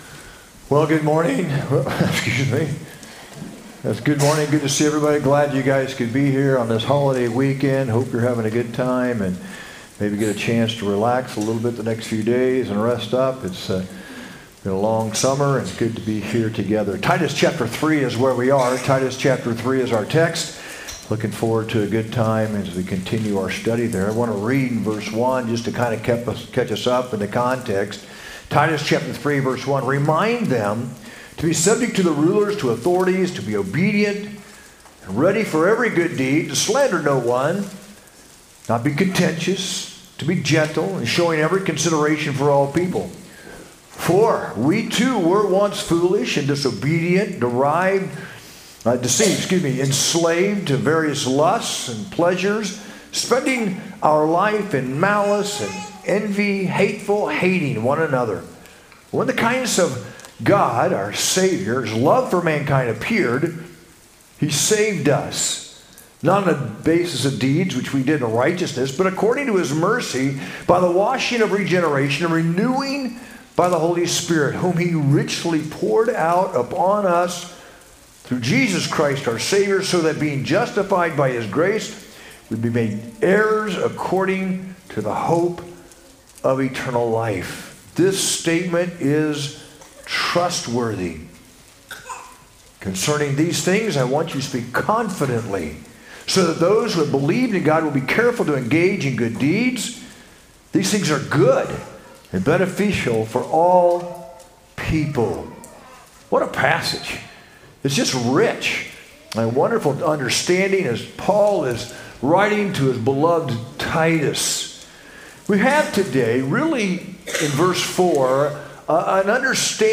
sermon-8-31-25.mp3